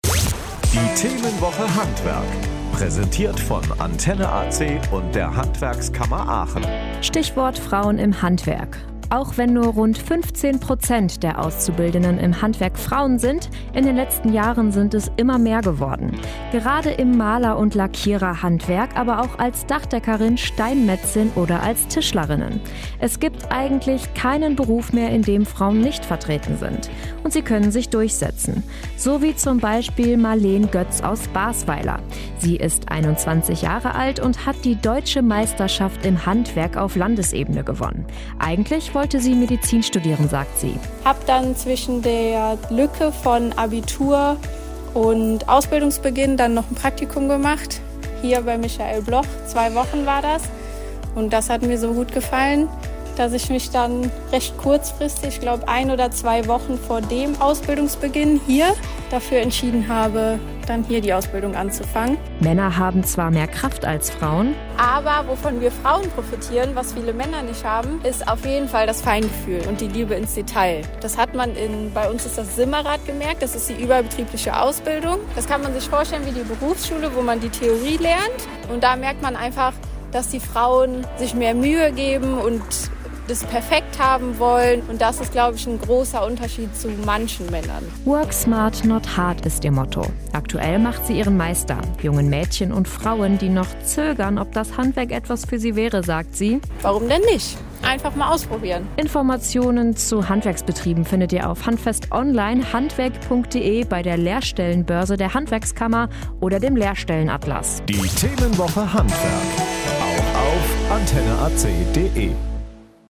Radiobeiträge: Frauen im Handwerk